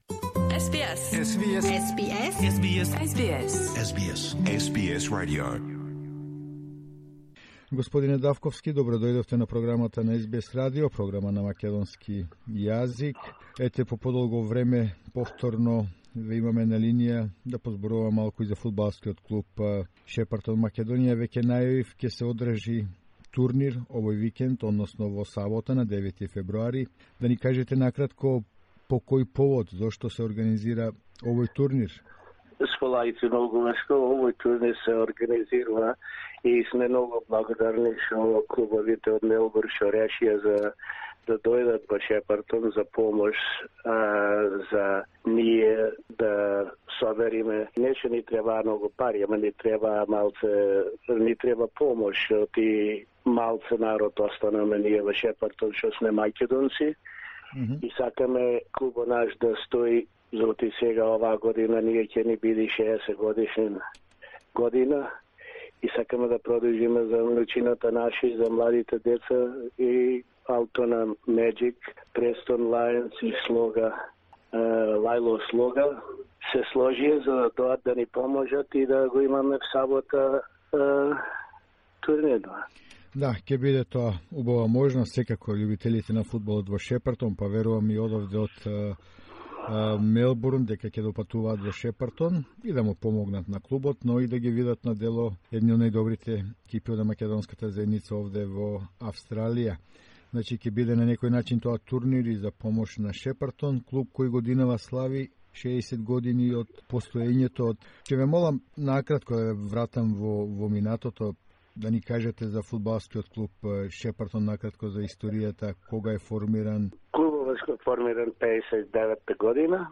On the occasion of the great jubilee FC Shepherton is organizing a tournament with the teams of Altona Magic Vardar, Preston Macedonia and Lallor Sloga. The tournament will also be used to support Shepparton SC Macedonia. A conversation